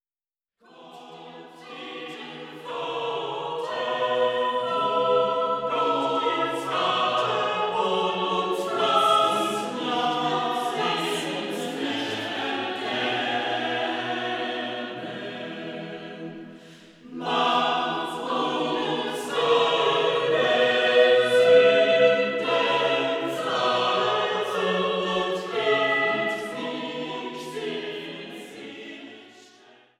Chorwerke auf Gesänge Martin Luthers